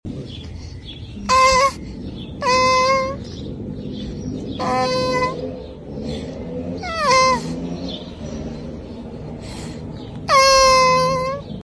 Turtle Making Funny Sound meme sound effects free download